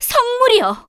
cleric_f_voc_skill_relicoflightning.wav